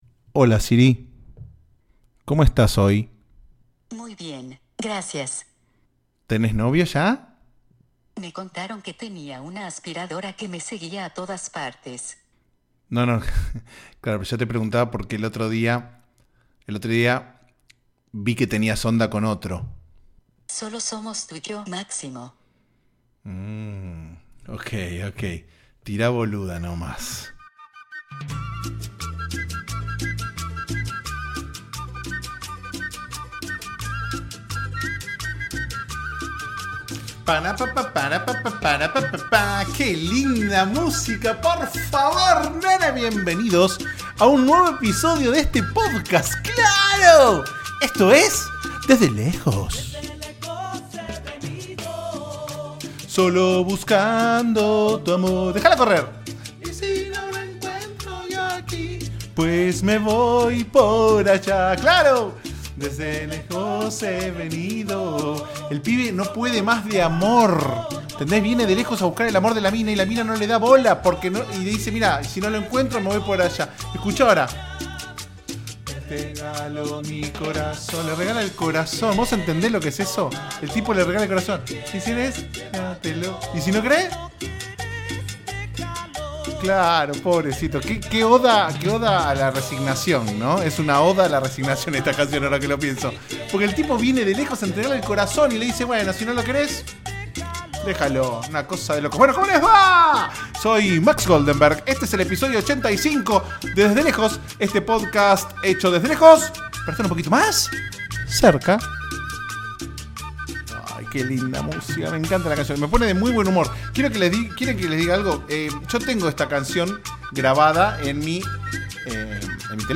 A pedido del público volvimos a interpretar canciones.